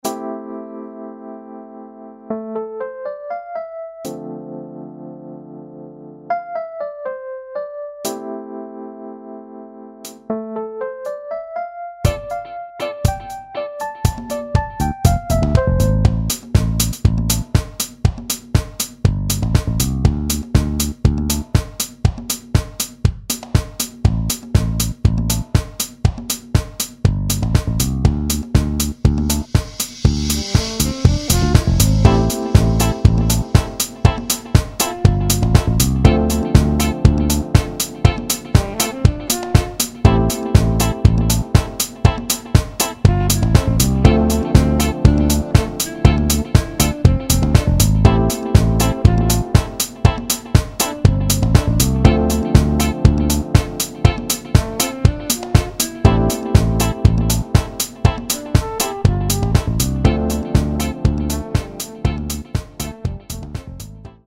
show_dance_clip_2.mp3